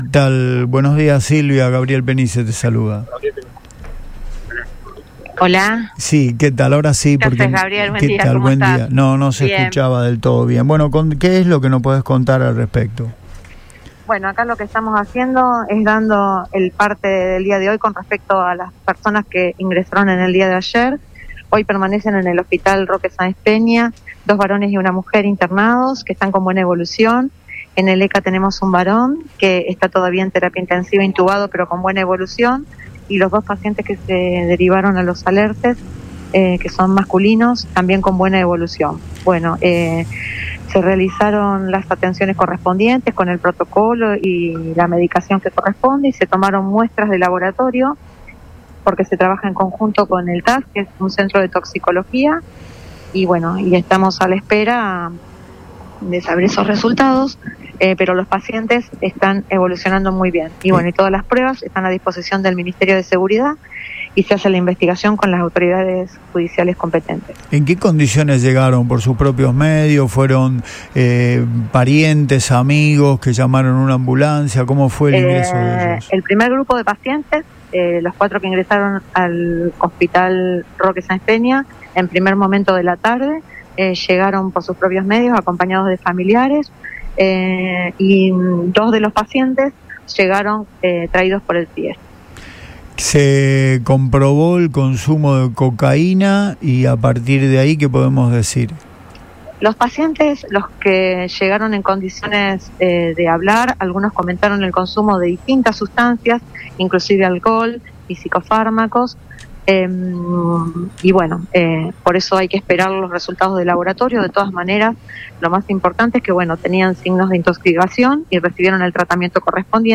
EN RADIO BOING
explicó Silvia Marmiroli, subsecretaria de Salud de Rosario en diálogo